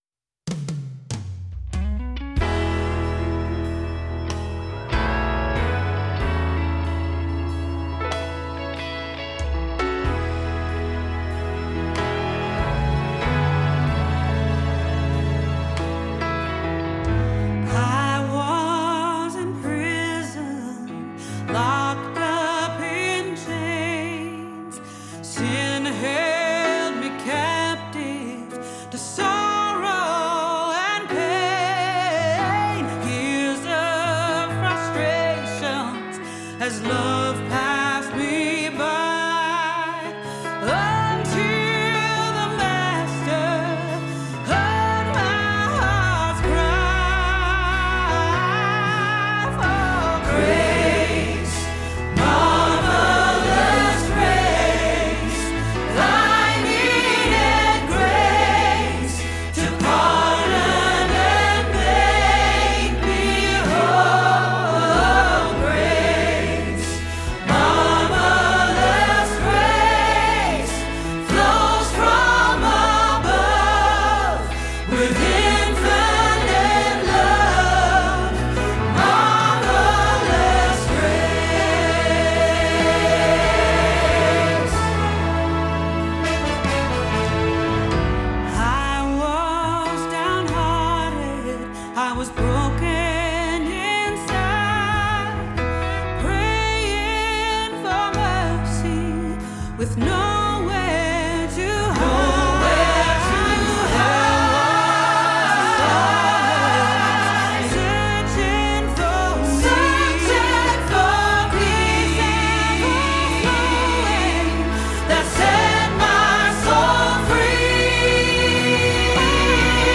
Grace with Grace Greater Than Our Sin – Soprano – Hilltop Choir
02-Grace-with-Grace-Greater-Than-Our-Sin-Soprano-Rehearsal-Track.mp3